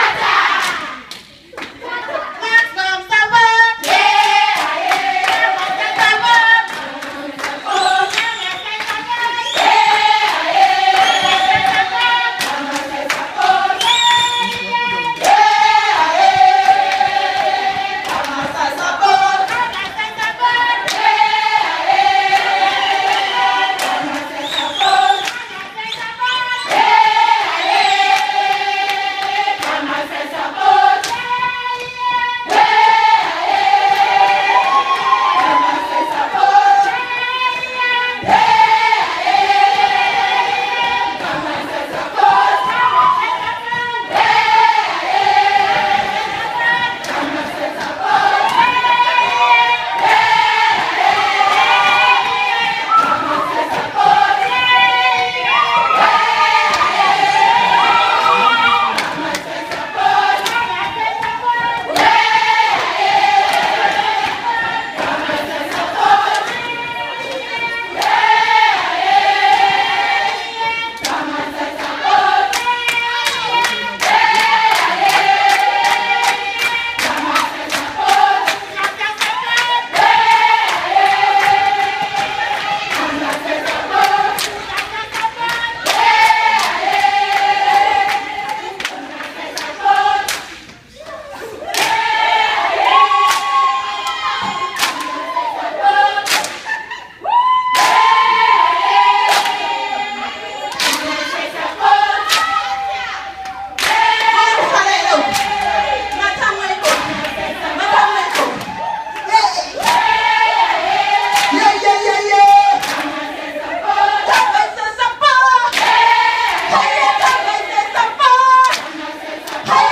Their songs traveled from somber to soaring, taking good time between points on an ethereal journey.
And they are singing…